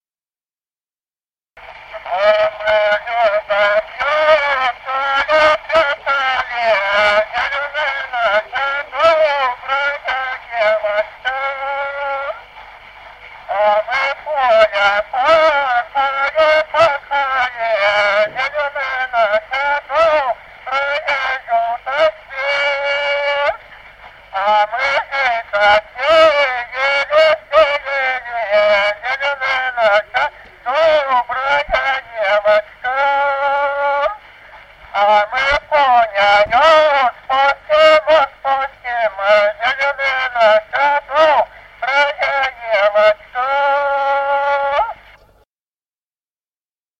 Народные песни Стародубского района «А мы лёдо прятали», юрьевская таночная.